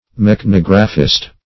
Search Result for " mechanographist" : The Collaborative International Dictionary of English v.0.48: Mechanographist \Mech`an*og"ra*phist\ (m[e^]k`an*[o^]g"r[.a]*f[i^]st), n. An artist who, by mechanical means, multiplies copies of works of art.